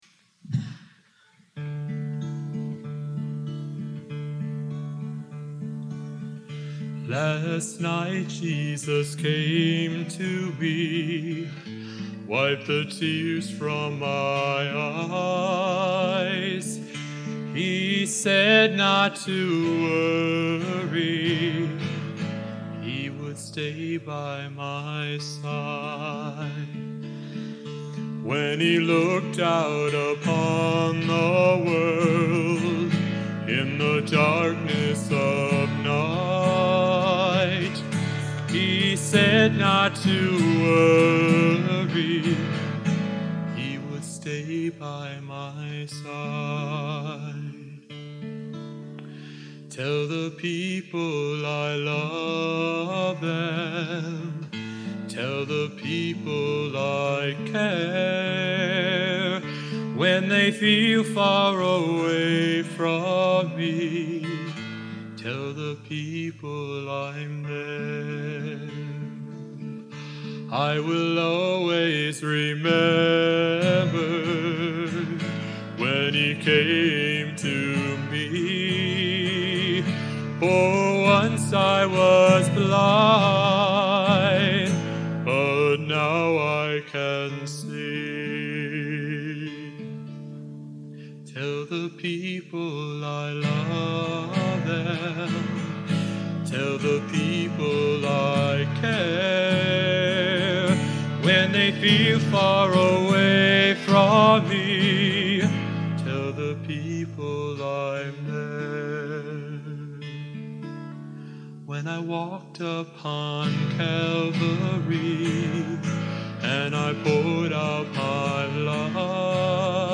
Genre: Modern Sacred | Type: